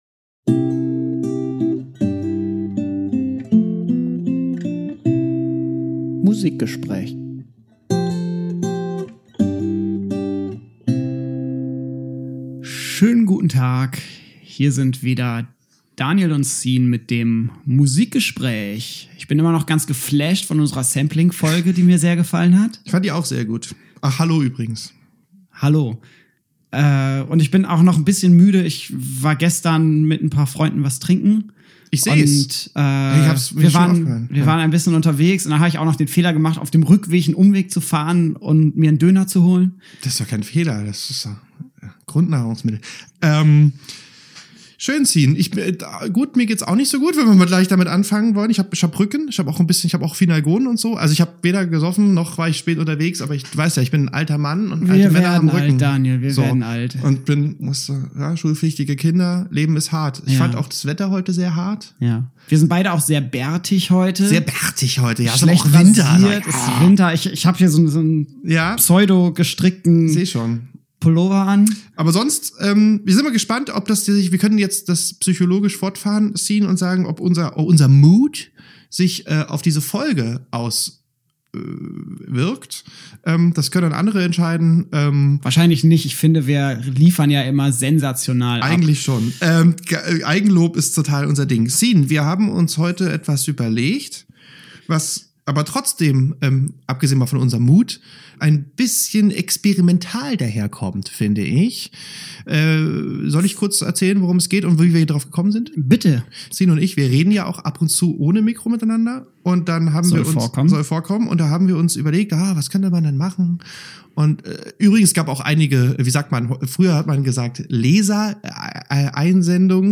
Was für Musik existierte vor 100 Jahren? In diesem Musikgespräch dreht sich alles um Musik im Jahr 1920 - von Opern in Nachkriegsdeutschland über die zunehmende Technisierung von Musik bis hin zu ersten Blues-Aufnahmen.